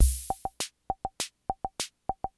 Retronome – A Versatile Analog Drum Machine for My Hammond Organ
In comparison, analog drum machines from the 1970s typically used transistor and diode based control logic for the rhythms, and analog transistor circuitry to produce percussion-like sounds.
basic-western.wav